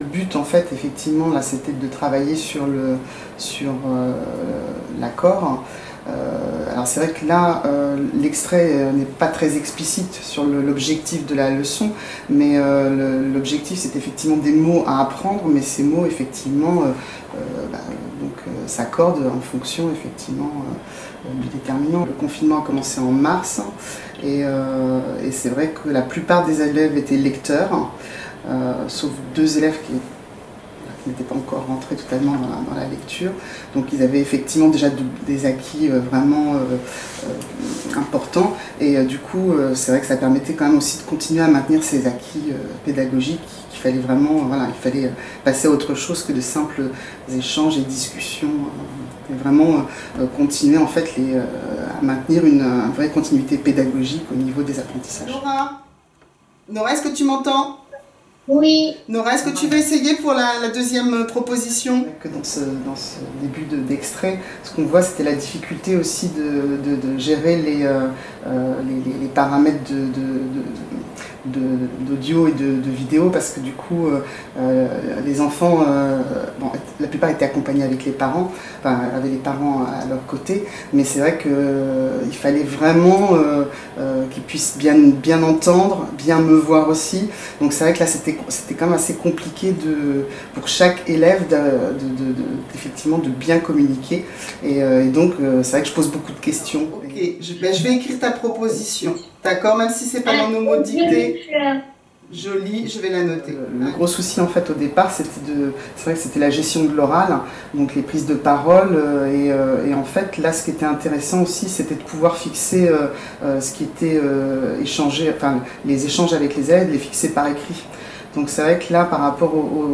Entretien avec l'enseignant